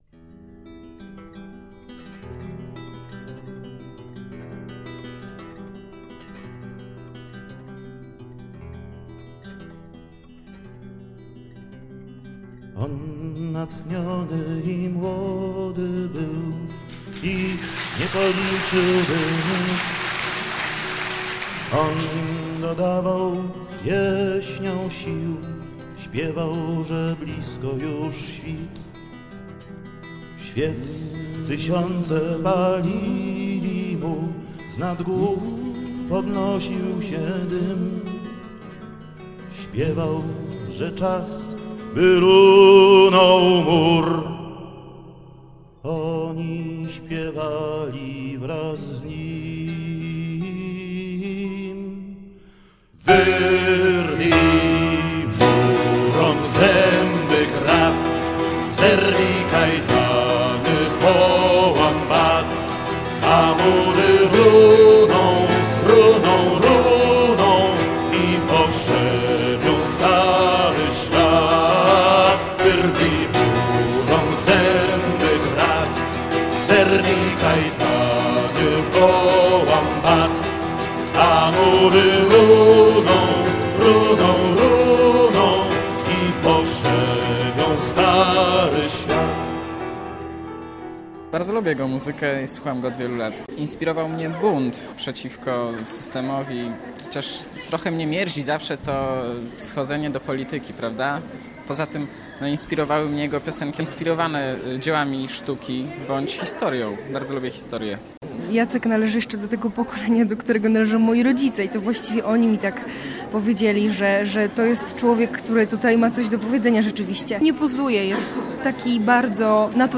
Jacek Kaczmarski - audycja dokumentalna